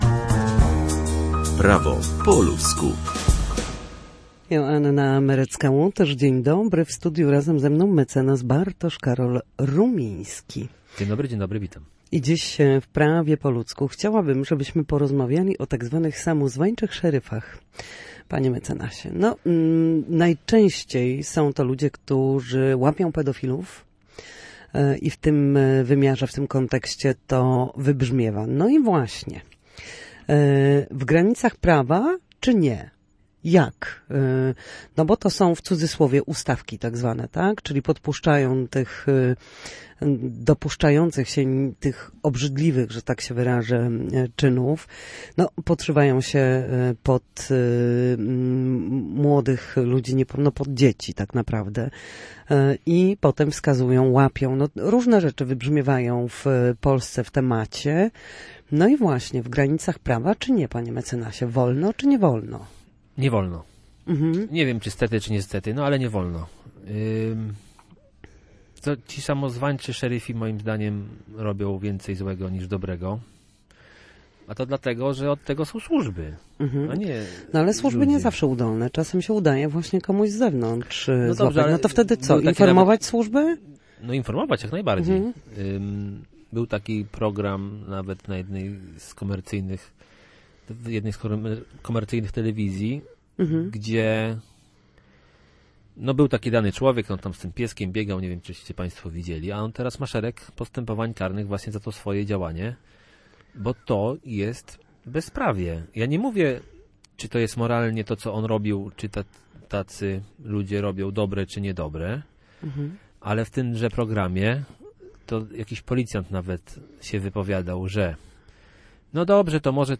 W każdy wtorek o godzinie 13:40 na antenie Studia Słupsk przybliżamy Państwu meandry prawa. W naszym cyklu prawnym gościmy ekspertów, którzy odpowiadają na jedno konkretne pytanie związane z zachowaniem w sądzie lub podstawowymi zagadnieniami prawnymi.